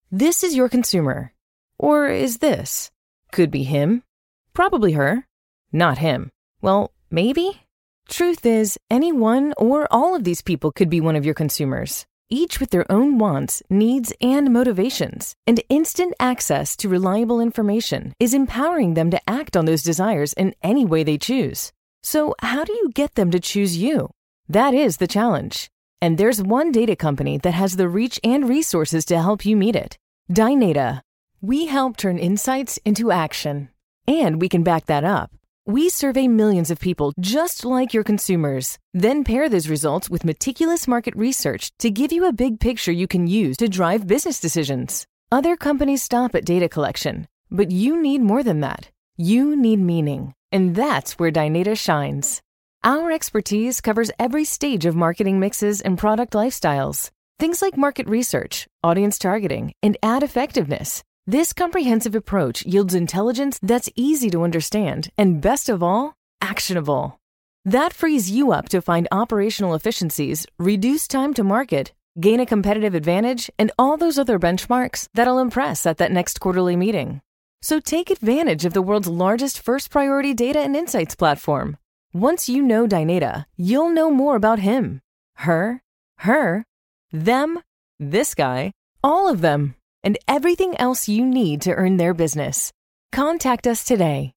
Tech Video
Neutral North American
Young Adult